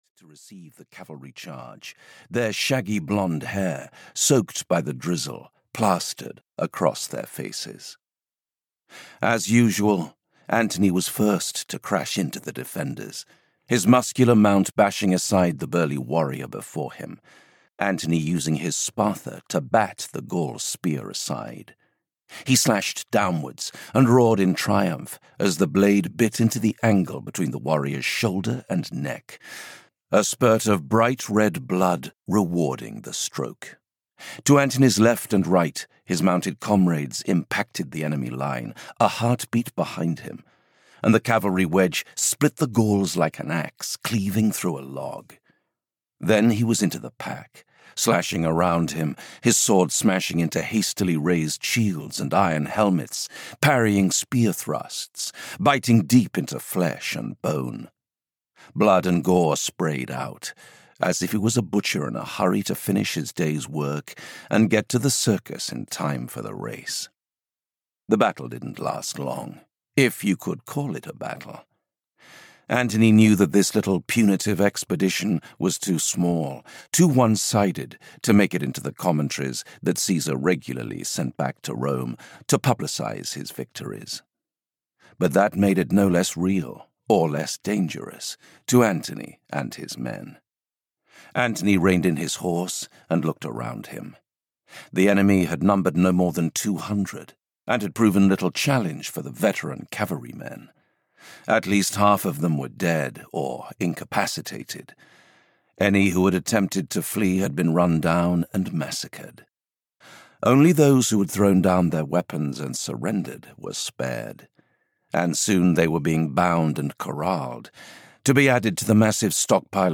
Caesar's General (EN) audiokniha
Ukázka z knihy